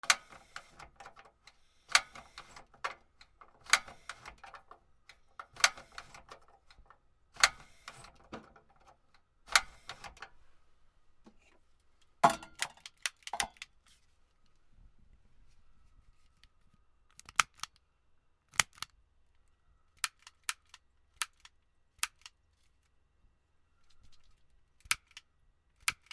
ディスポーザブルライター(チャッカマン)
/ M｜他分類 / L01 ｜小道具 / 文房具・工作道具
『チ』